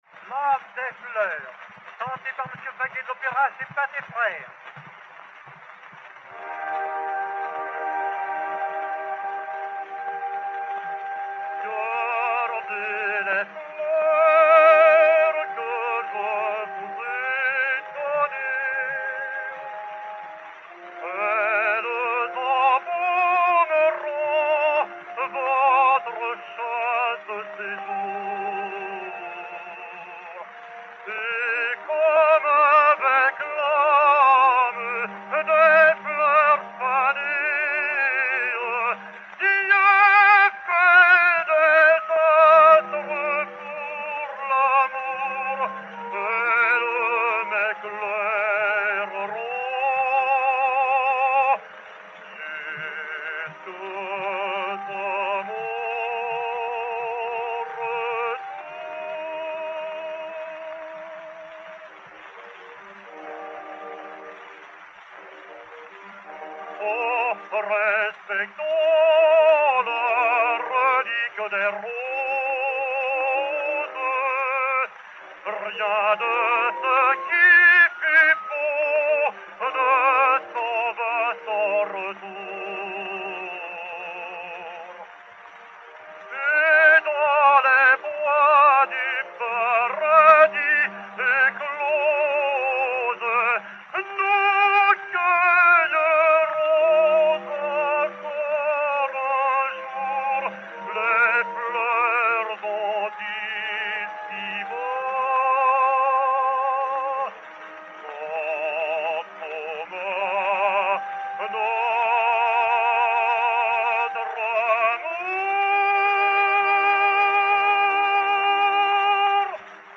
Mélodie
ténor, avec Orchestre